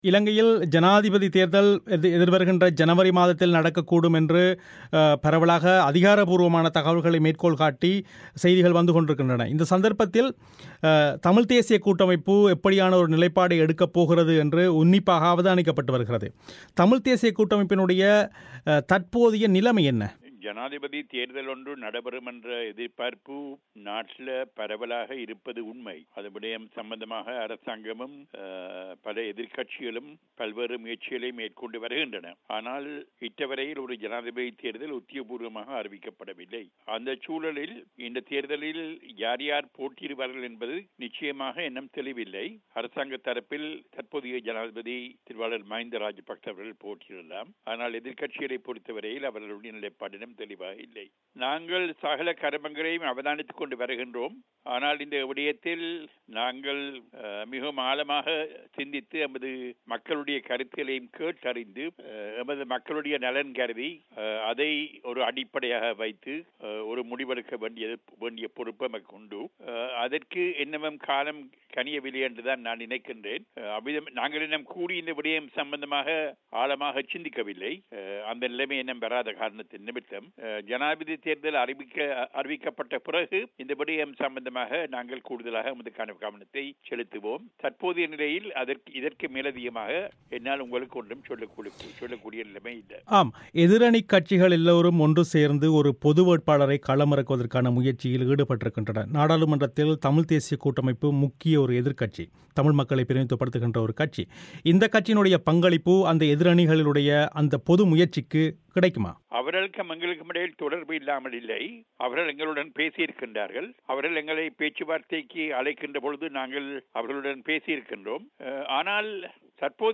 இலங்கையில் நடக்கவுள்ள ஜனாதிபதி தேர்தலில் தமிழ் மக்களை பிரதிநிதித்துவப்படுத்தும் பிரதான அரசியல்கட்சியான தமிழ்த் தேசியக் கூட்டமைப்பு யாரை ஆதரிக்கும் என்ற பிபிசி தமிழோசையின் கேள்விகளுக்கு அக்கட்சியின் தலைவர் இரா. சம்பந்தன் அளித்த பதில்கள்